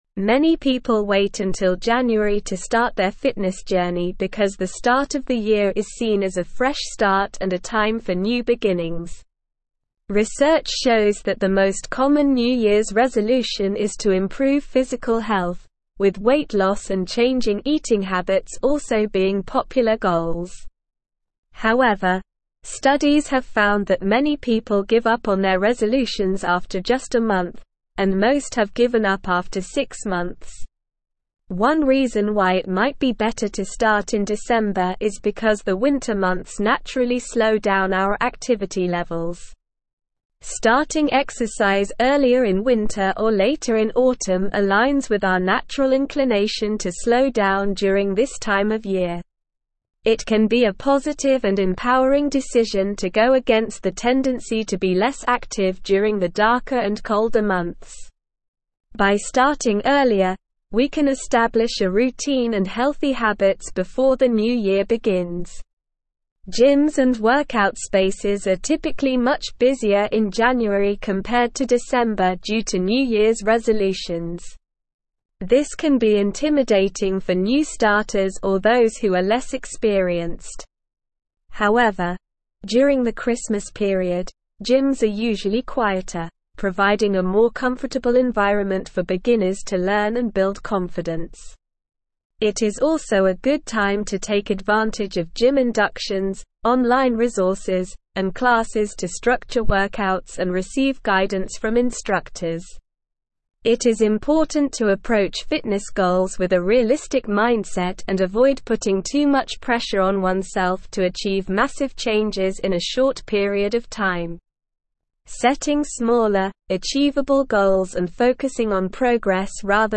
Slow
English-Newsroom-Advanced-SLOW-Reading-Starting-Your-Fitness-Journey-Why-December-is-Ideal.mp3